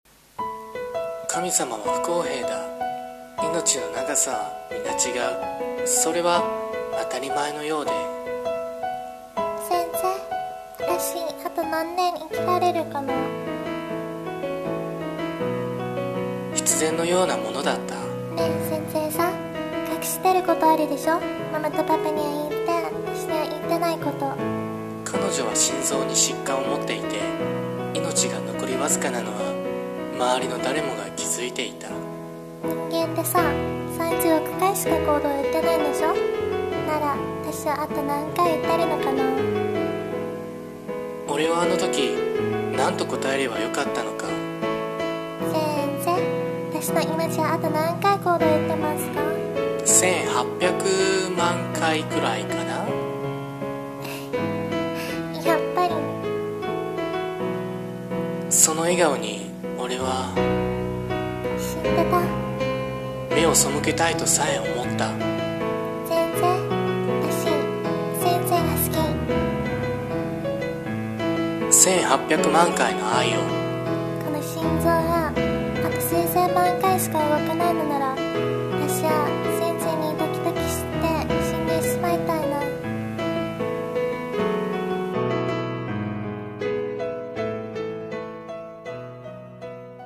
映画予告風声劇】1800万回の愛を
二人声劇